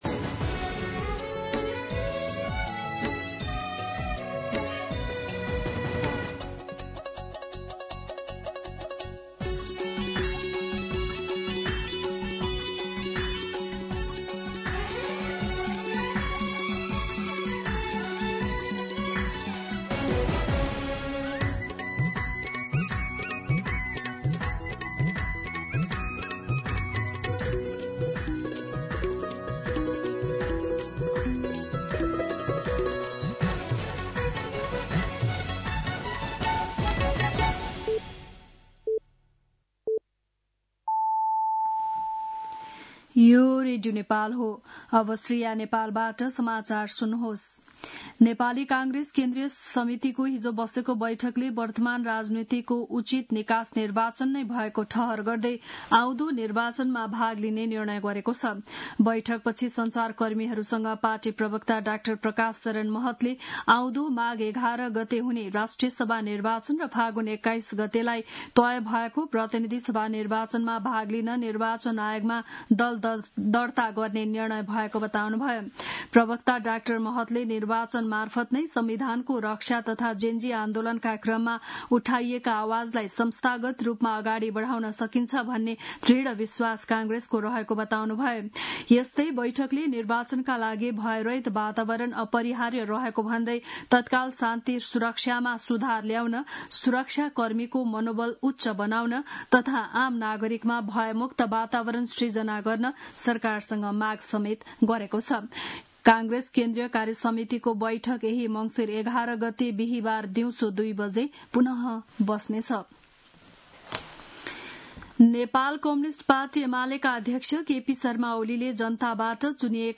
बिहान ११ बजेको नेपाली समाचार : १८ पुष , २०२६